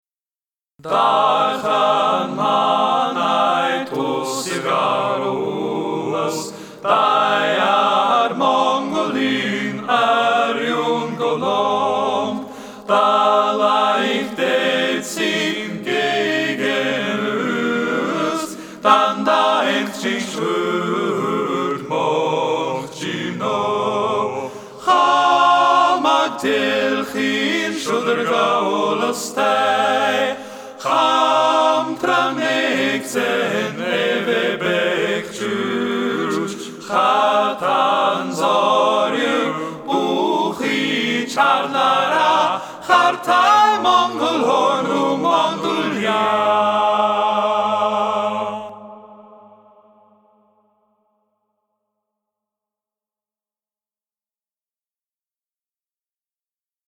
In my family, it's not uncommon to bring back musical recordings as souvenirs, so I decided to sing and record a song that could pass as a souvenir from Mongolia.
(Recorded in Audacity using Blue 8-ball microphone and small Behringer analog mixer)
Genre: World My "Studio" A cheap mic and a free audio program.